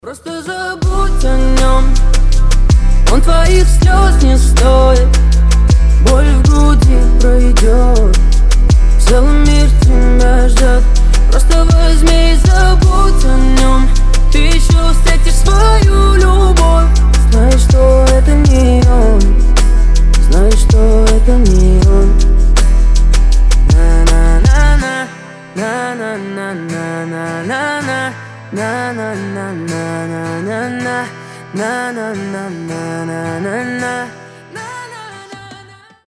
• Качество: 320, Stereo
гитара
мужской голос
лирика